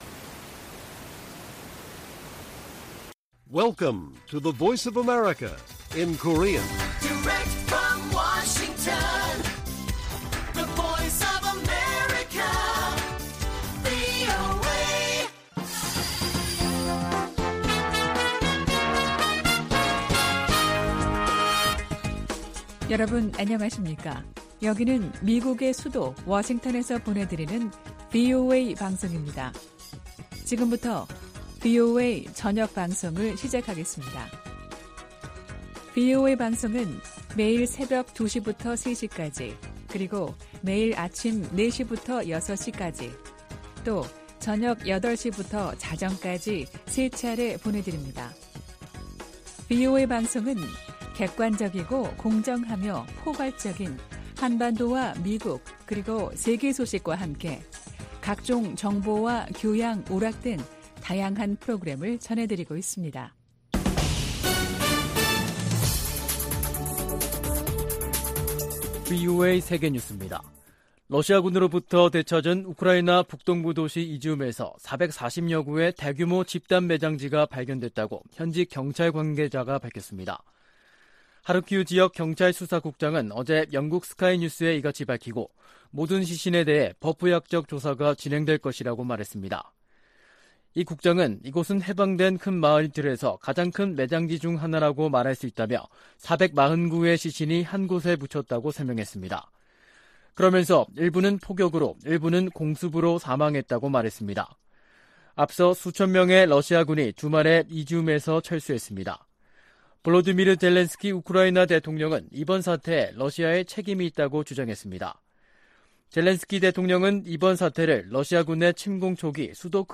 VOA 한국어 간판 뉴스 프로그램 '뉴스 투데이', 2022년 9월 16일 1부 방송입니다. 리잔수 중국 전국인민대표대회 상무위원장은 북핵 문제에 관해 한반도 평화체제 구축이 당사국들의 이익에 부합한다는 원칙을 거듭 밝혔습니다. 미 국무부는 미한 확장억제전략협의체 회의에서 북한 위협에 맞서 협력을 확대하는 방안이 논의될 것이라고 밝혔습니다. 미국 전략사령관 지명자가 북한 핵 미사일 위협에 맞선 차세대 요격 미사일(NGI) 개발을 지지한다고 말했습니다.